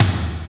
TOM TOM260PR.wav